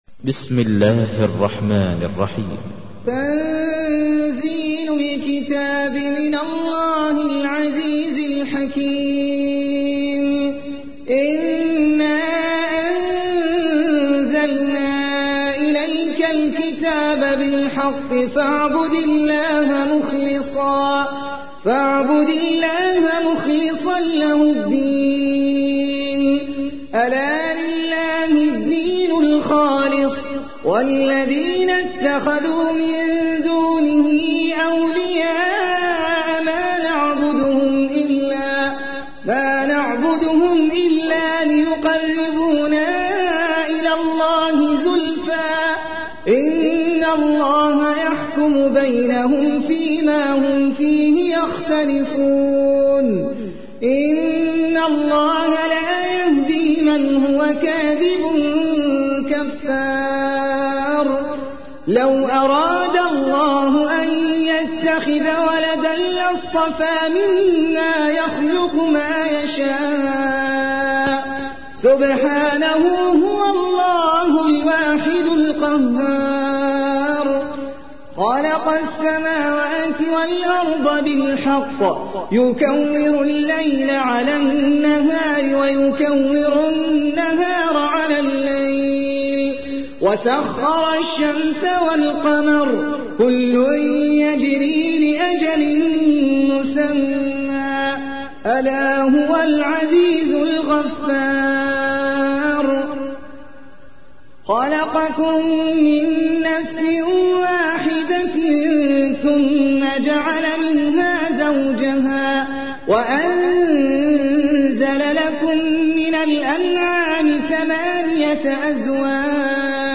تحميل : 39. سورة الزمر / القارئ احمد العجمي / القرآن الكريم / موقع يا حسين